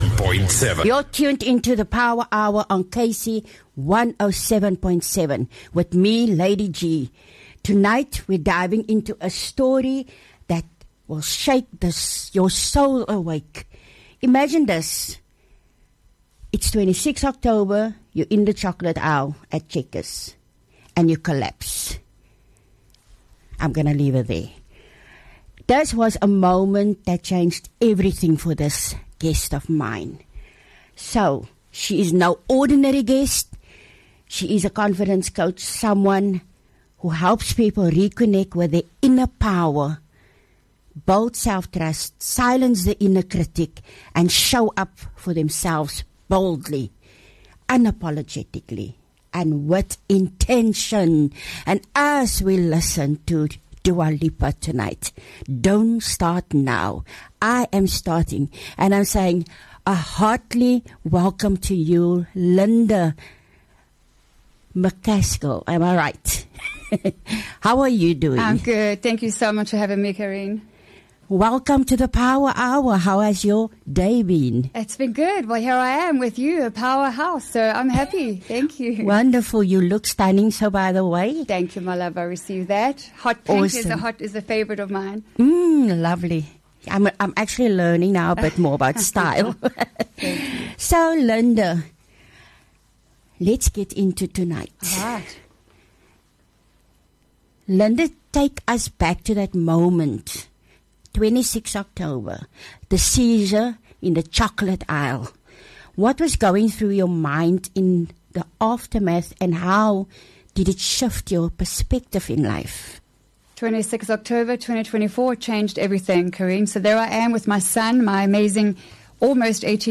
Inspiring Interview